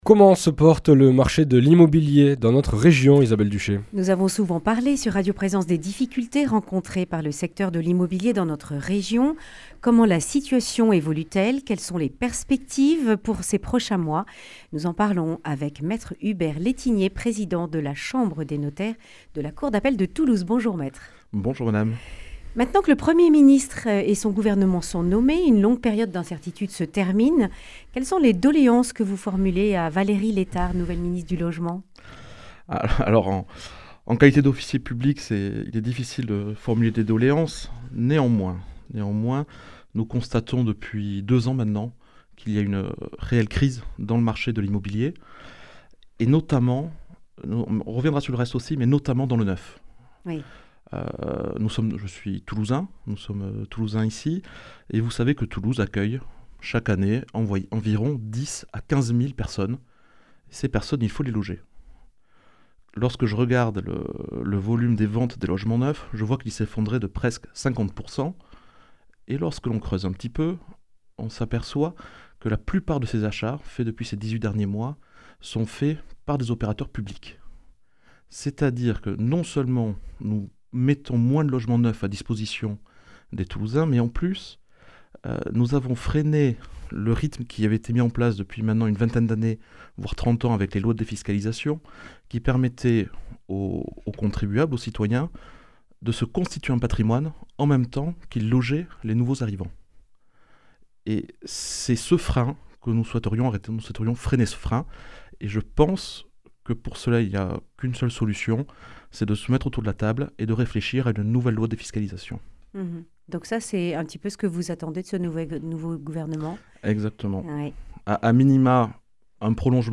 Accueil \ Emissions \ Information \ Régionale \ Le grand entretien \ Chambre des notaires de Toulouse, des attentes fortes pour le logement (...)